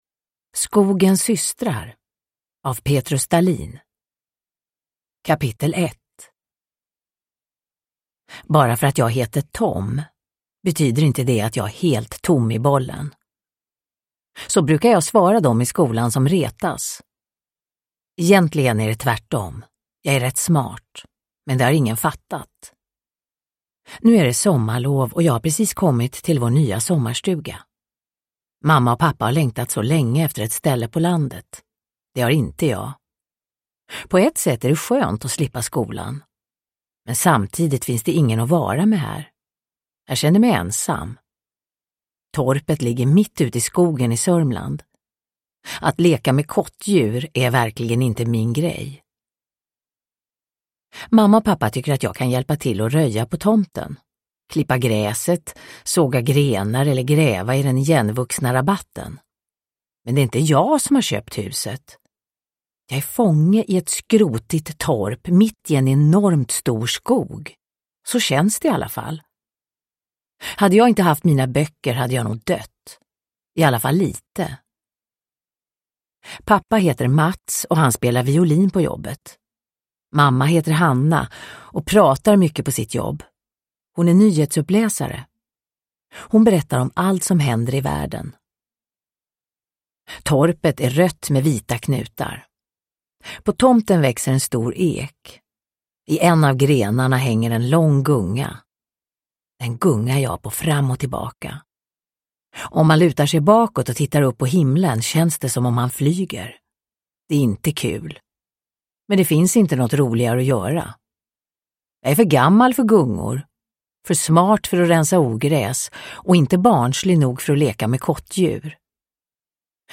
Skogens systrar – Ljudbok
Uppläsare: Marie Richardson